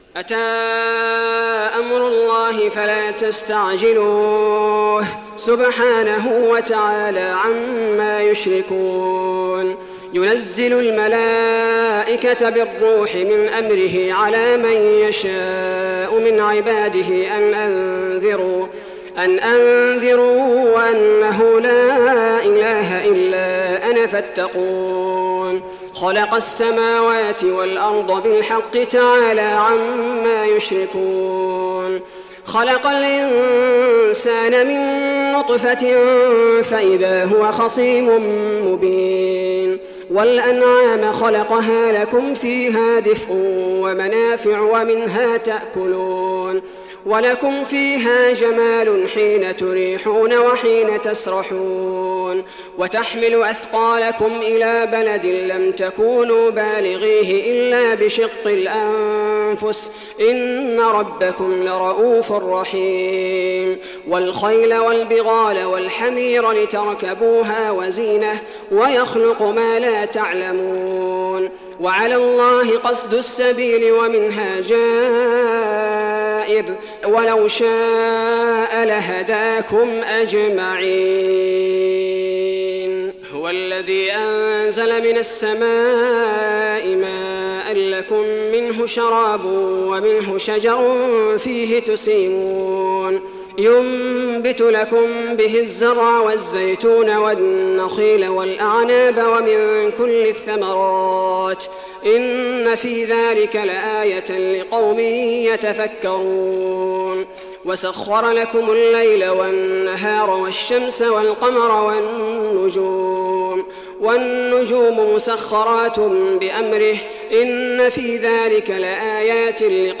تراويح رمضان 1415هـ سورة النحل كاملة Taraweeh Ramadan 1415H from Surah An-Nahl > تراويح الحرم النبوي عام 1415 🕌 > التراويح - تلاوات الحرمين